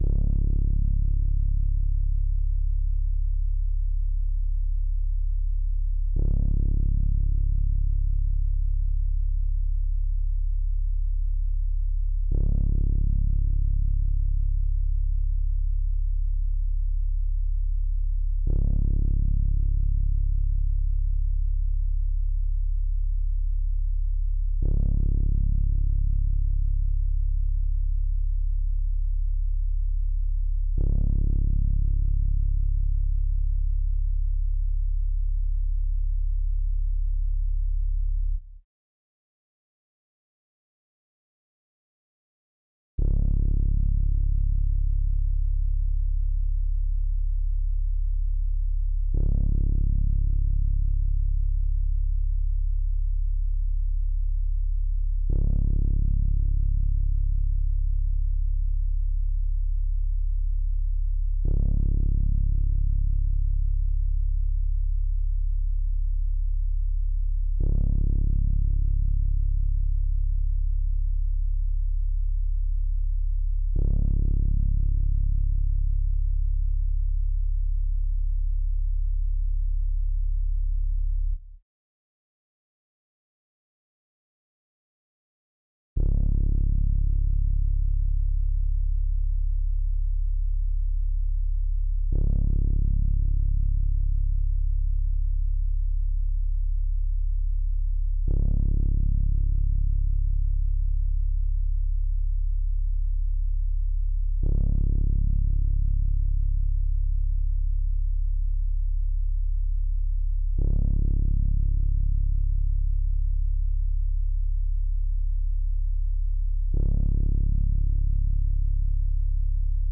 808s
TUPAC MURDER CONFESSION BASS.wav